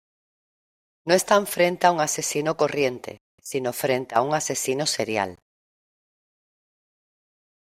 Pronounced as (IPA) /seˈɾjal/